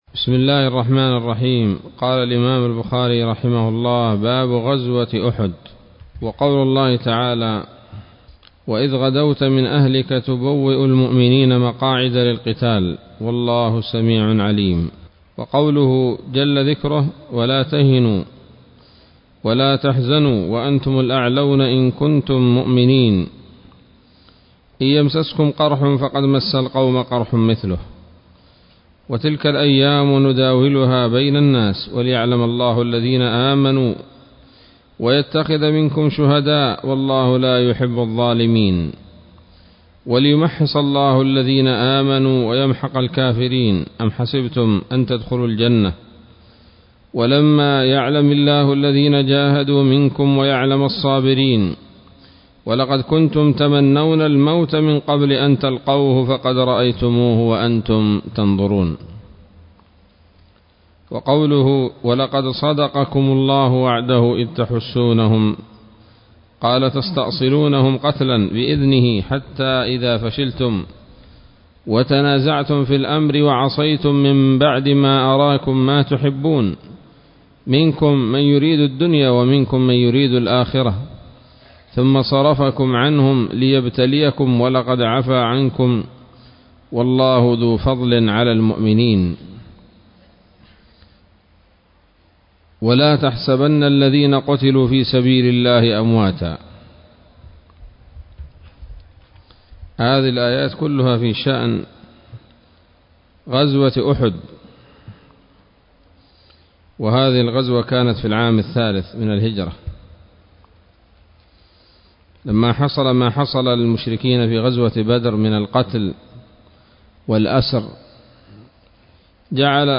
الدرس الثامن والعشرون من كتاب المغازي من صحيح الإمام البخاري